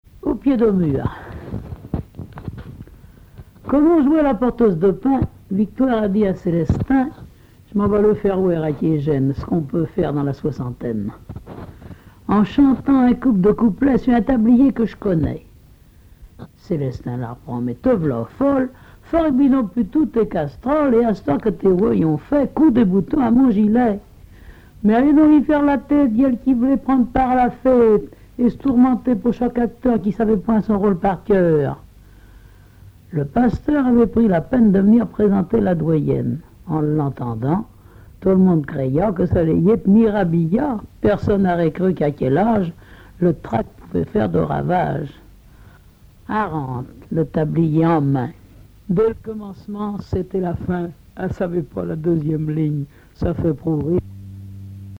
Localisation Chauvé
Langue Patois local
Genre récit